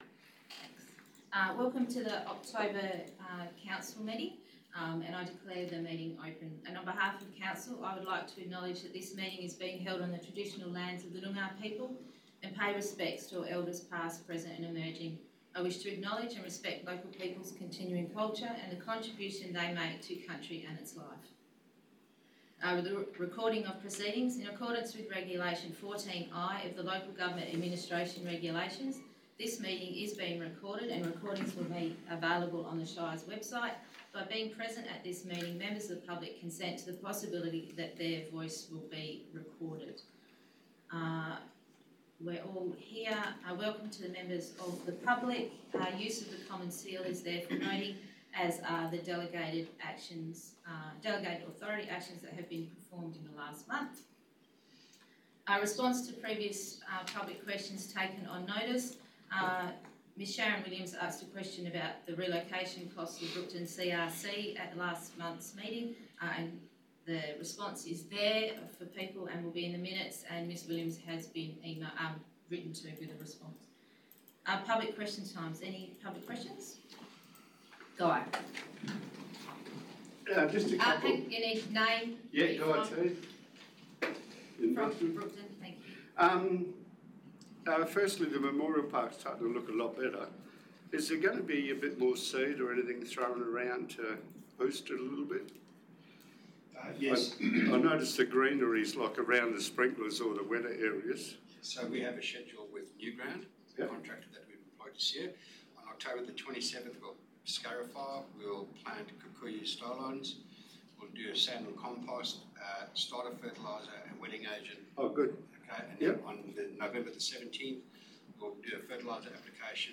16 October 2025 - Ordinary Meeting of Council » Shire of Brookton